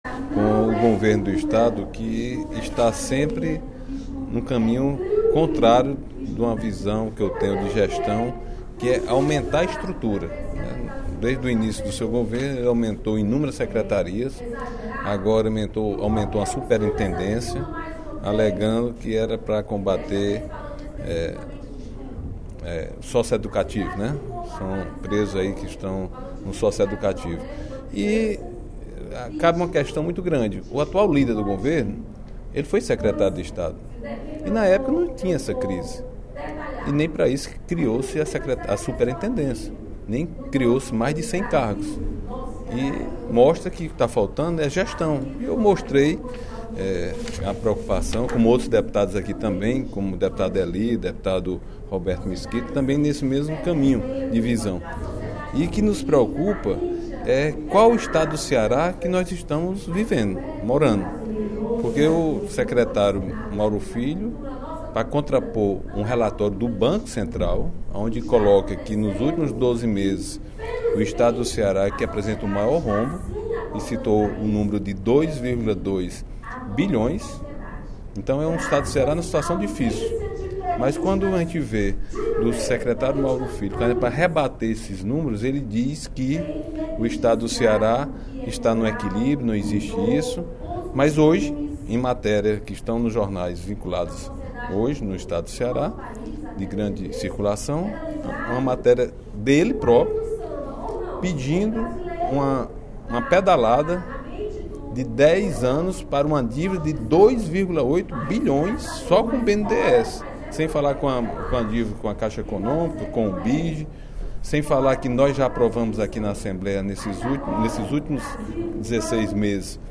O deputado Agenor Neto (PMDB) manifestou, no primeiro expediente da sessão plenária da Assembleia Legislativa desta sexta-feira (10/06), preocupação com as prioridades traçadas pelo Governo do Estado e criticou o aumento da estrutura do Executivo.